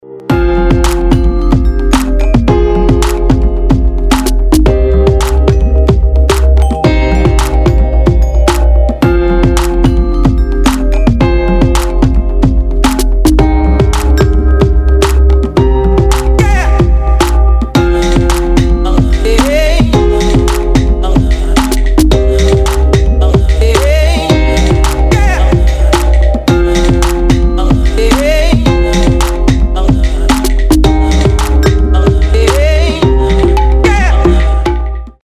• Качество: 256, Stereo
красивые
без слов
house
Очень приятная хаус музыка